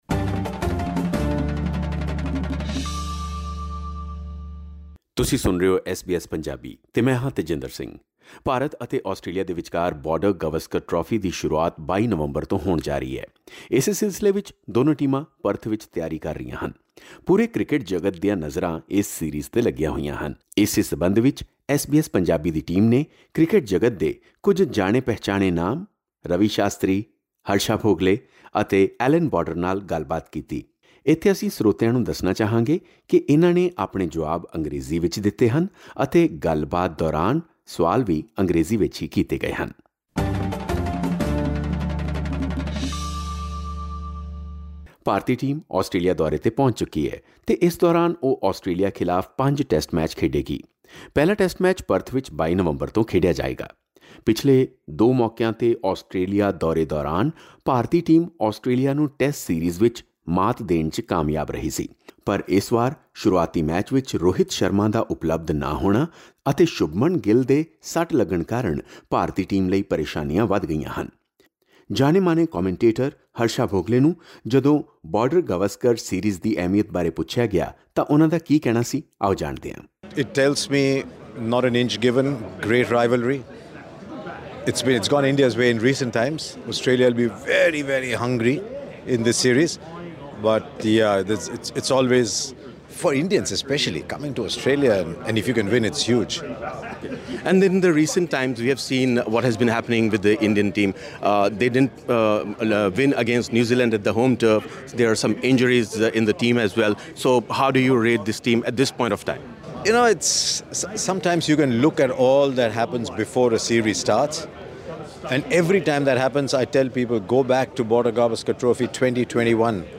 ਇਨ੍ਹਾਂ ਕ੍ਰਿਕਟ ਕੁਮੈਂਟੇਟਰਸ ਨਾਲ ਹੋਰ ਗੱਲਬਾਤ, ਇਸ ਪੌਡਕਾਸਟ ਰਾਹੀਂ ਸੁਣੋ।